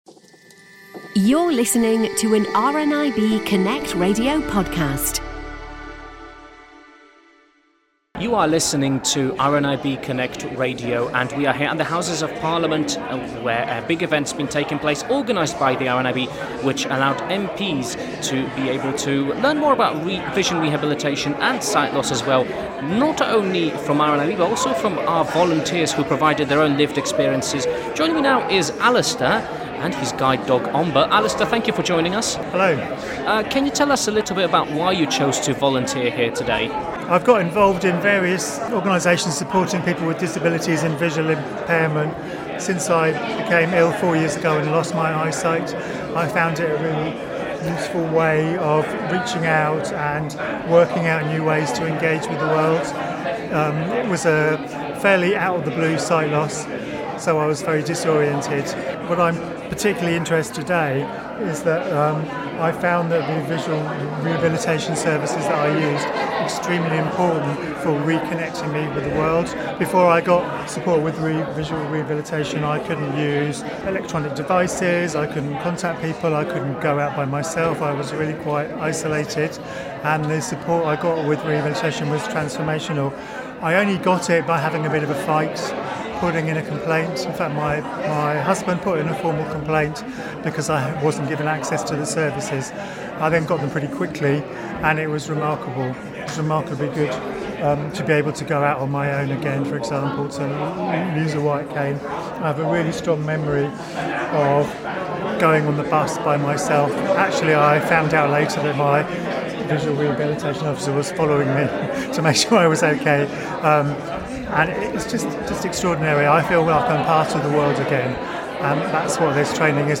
chats to another volunteer who came along to provide his knowledge and experience to the visiting MPs.